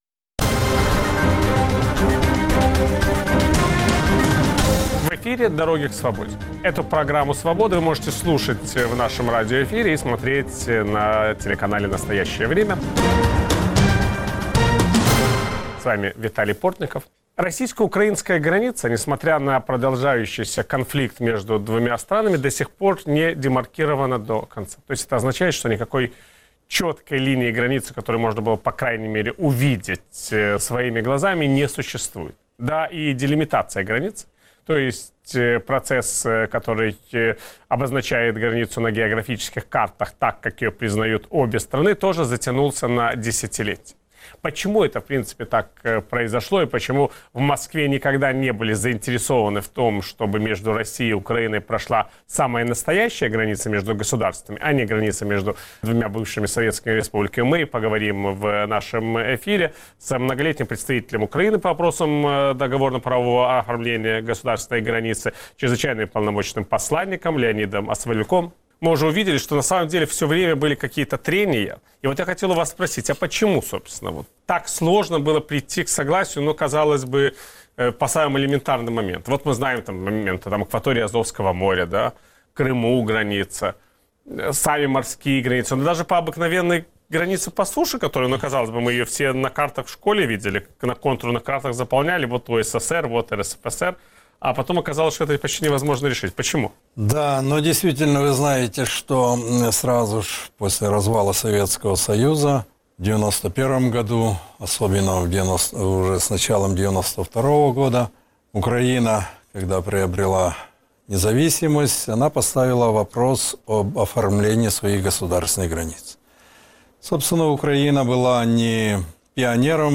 В киевской студии Радио Свобода обсуждаем Украину после Майдана. Удастся ли украинцам построить демократическое европейское государство? Как складываются отношения Украины и России?
Все эти и многие другие вопросы обсуждаем с политиками, журналистами и экспертами.